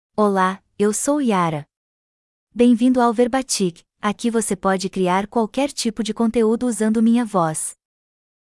Yara — Female Portuguese (Brazil) AI Voice | TTS, Voice Cloning & Video | Verbatik AI
Yara is a female AI voice for Portuguese (Brazil).
Voice sample
Female
Yara delivers clear pronunciation with authentic Brazil Portuguese intonation, making your content sound professionally produced.